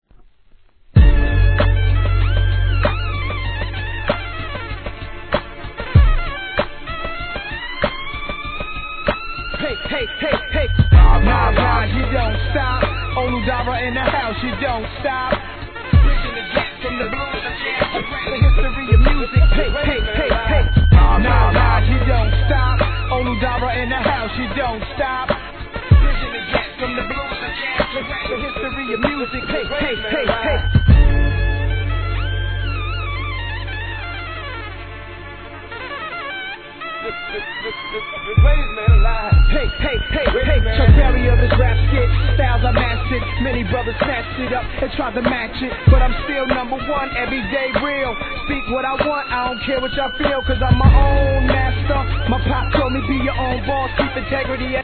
HIP HOP/R&B
これがまた激渋い仕上がりです!!